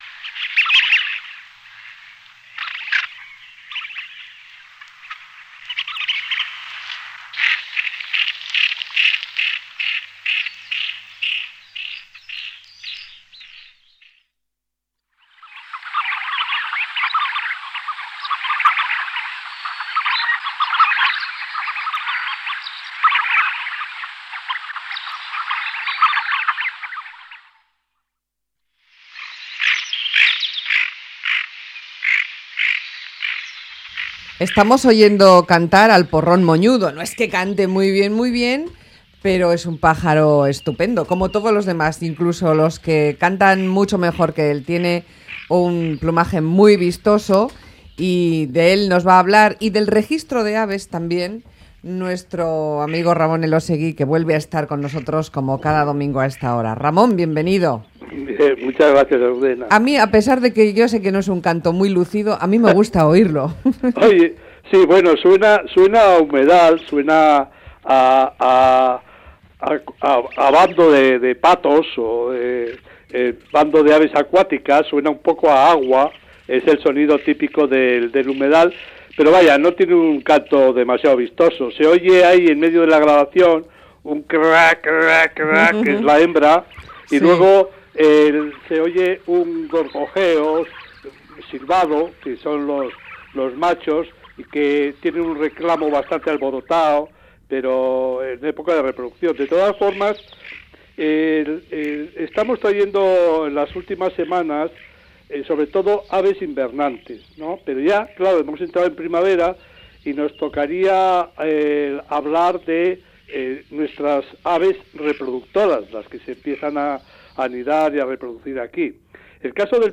Una especie de bello plumaje y canto poco armónico.